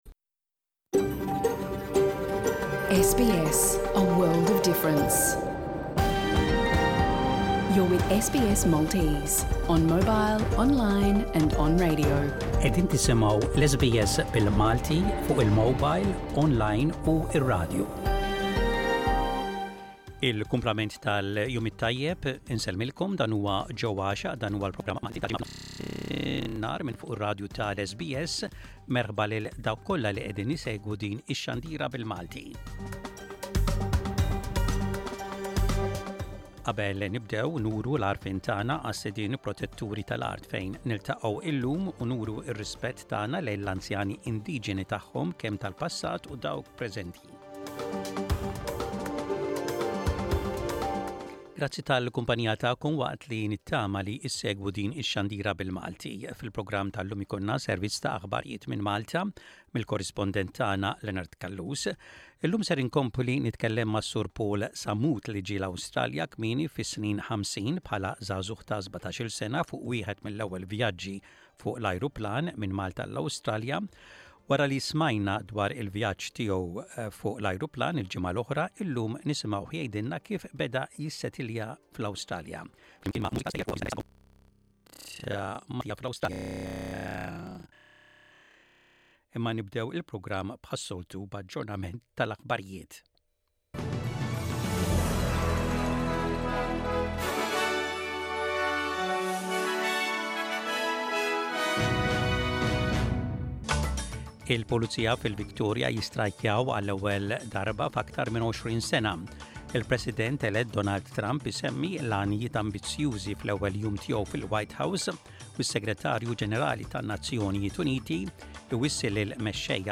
Jinkludi l-aħbarijiet mill-Awstralja